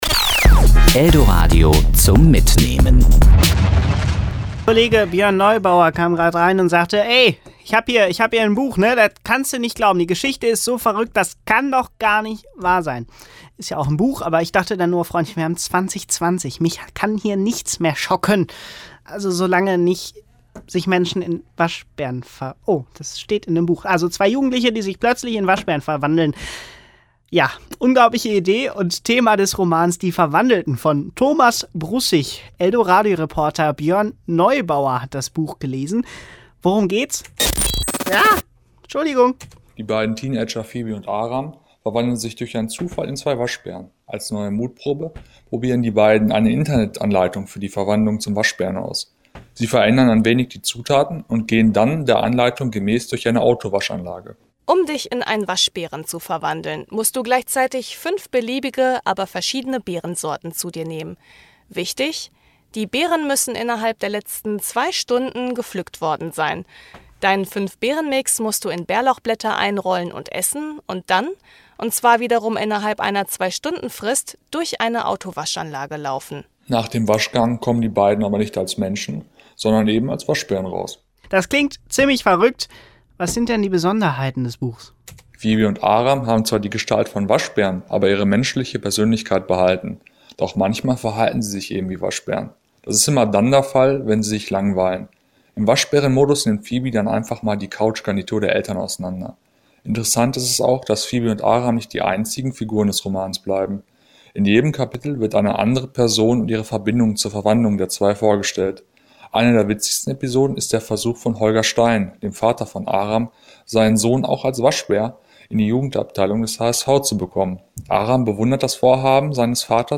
Buchrezension - "Die Verwandelten"
Serie: Kollegengespräch